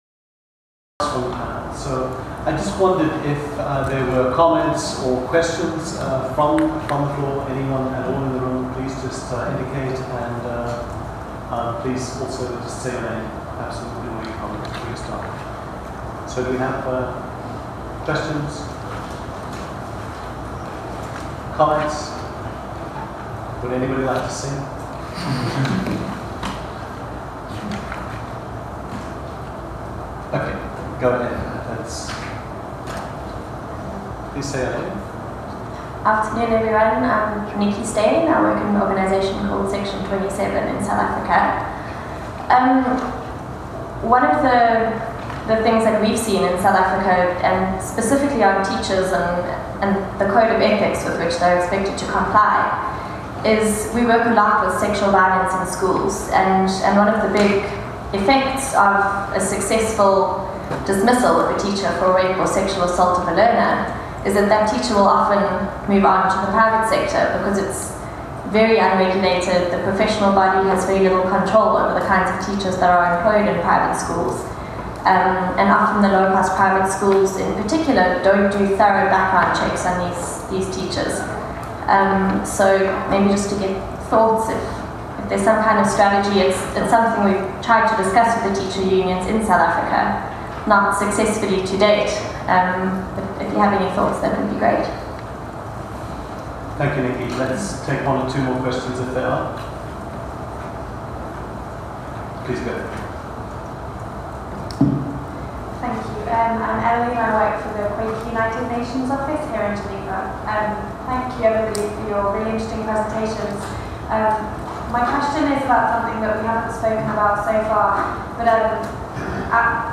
On 12 June 2014, during the June Session of the Human Rights Council, the Portuguese Mission, together with Privatisation in Education Research Initiative (PERI) and the Global Initiative for Economic, Social and Cultural Rights (GI-ESCR), convened a side-event on privatisation and its impact on the right to education at Palais des Nations in Geneva.
The presentations by the panel were followed by a series of questions and answers with the participants.